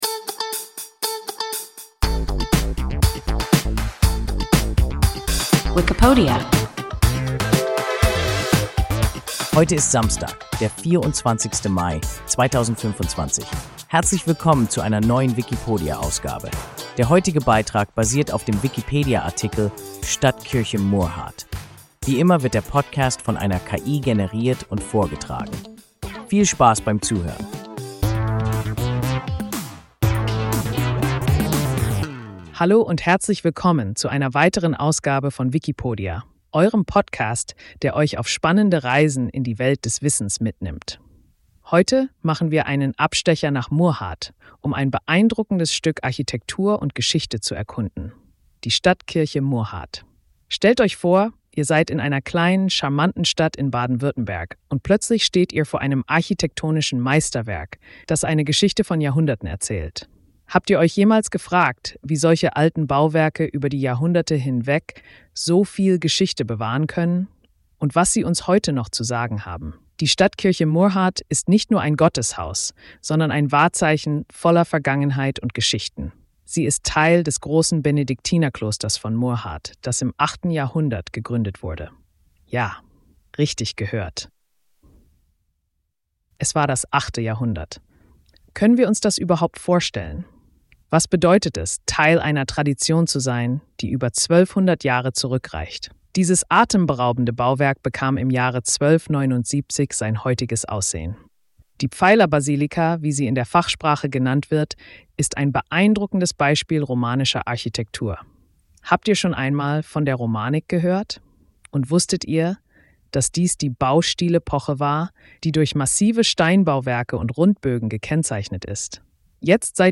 Stadtkirche Murrhardt – WIKIPODIA – ein KI Podcast